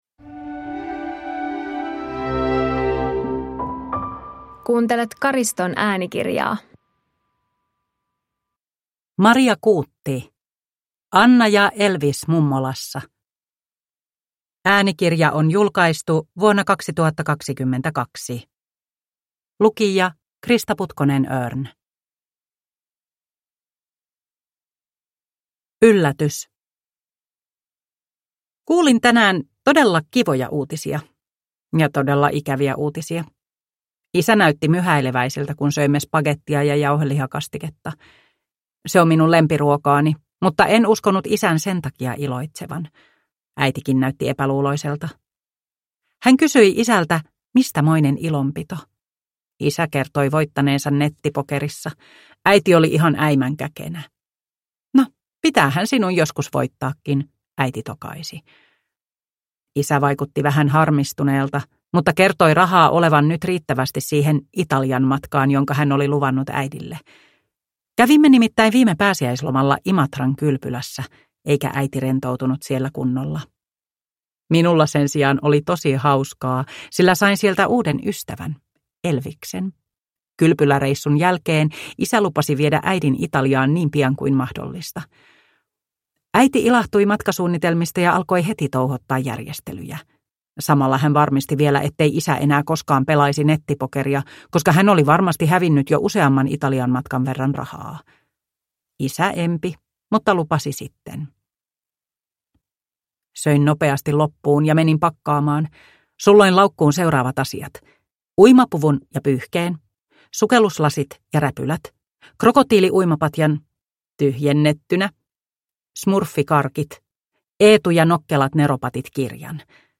Anna ja Elvis mummolassa – Ljudbok – Laddas ner